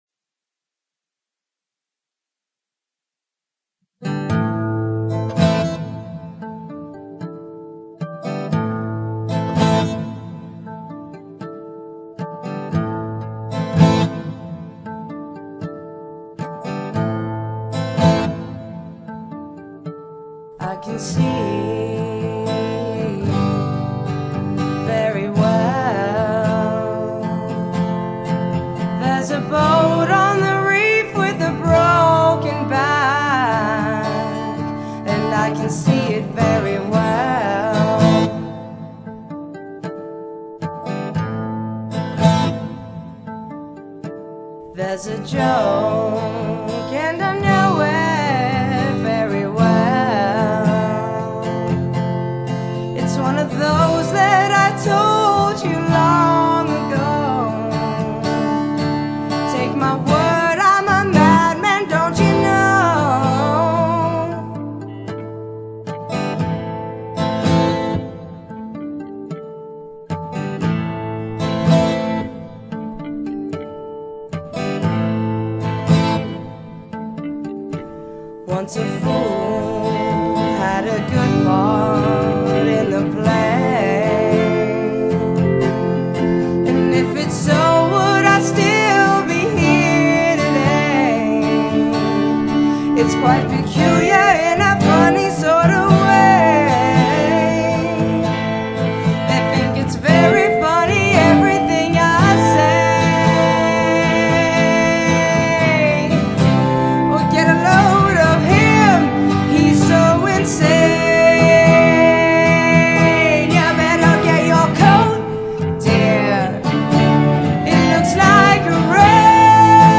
This song needs only final mixing to be finished.